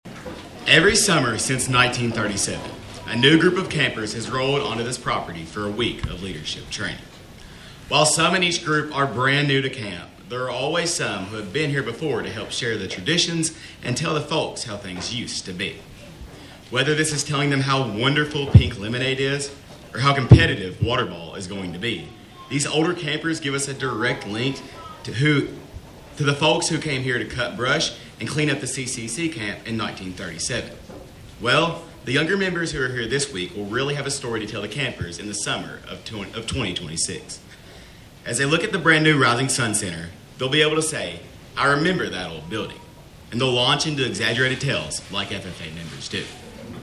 On July 18th, the camp held a groundbreaking ceremony for a new activity center.